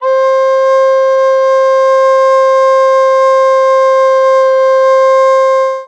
Flauta de pico en escala cromática tocando la nota C